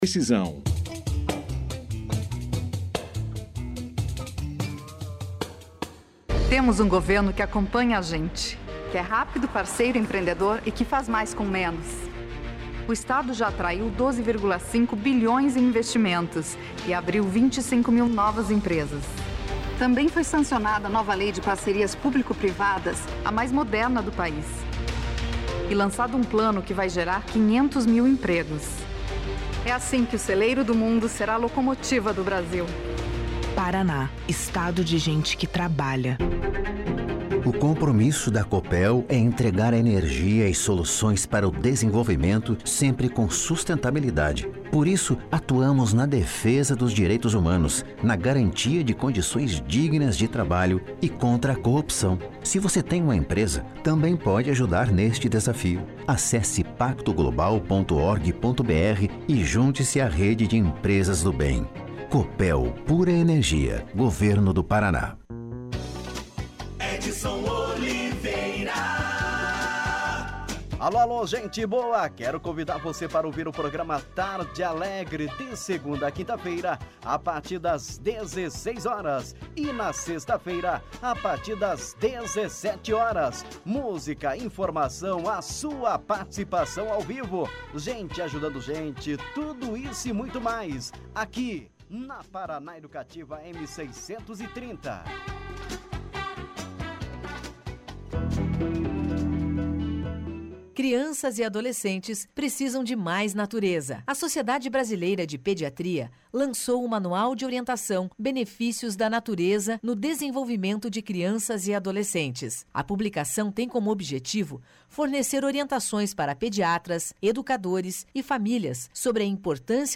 Os convidados iniciaram a entrevista explicando no que consiste o projeto, seus principais objetivos e de que maneira o combate ao bullying e à violência sexual infantil acontece na prática. Além de explicarem como reconhecer uma criança vítima desses tipos de agressões, os convidados falaram a respeito do papel do professor em conflitos fora da sala de aula. Confira aqui a entrevista na íntegra.